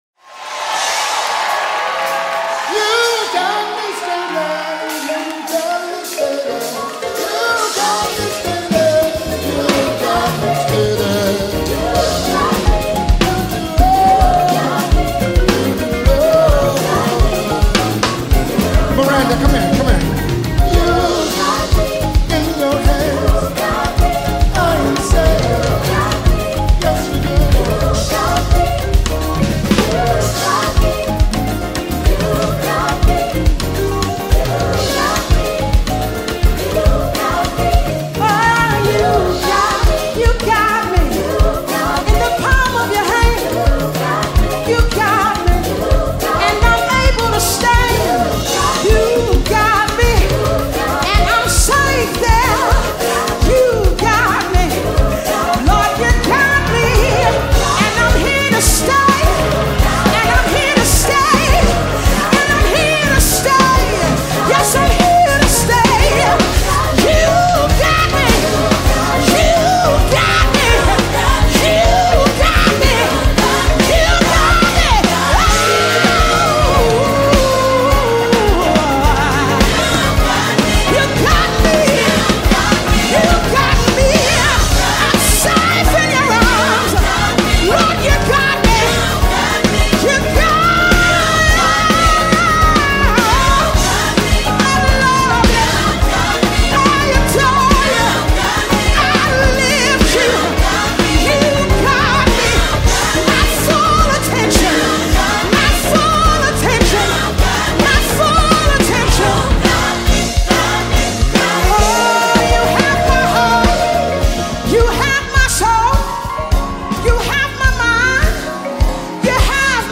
Mp3 Gospel Songs
offers another impressive and beautiful gospel melody